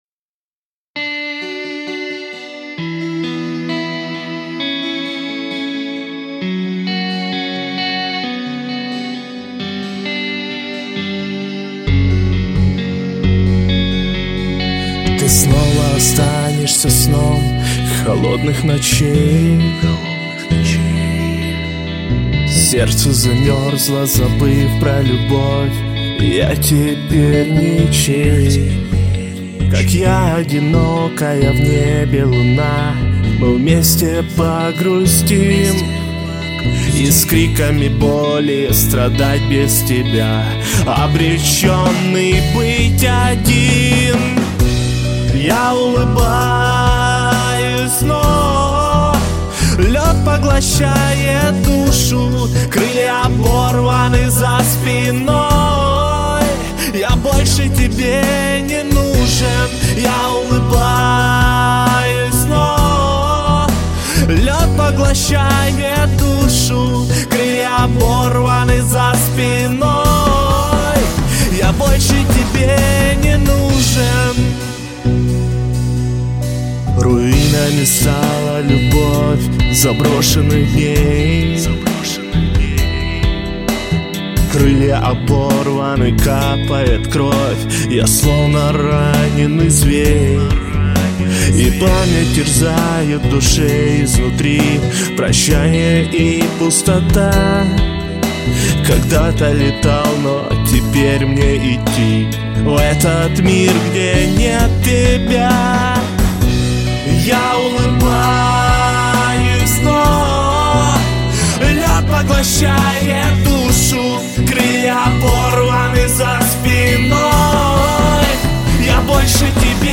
Вокал
Автор и исполнитель собственных песен.